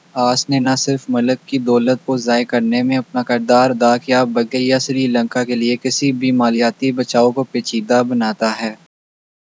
Spoofed_TTS/Speaker_02/272.wav · CSALT/deepfake_detection_dataset_urdu at main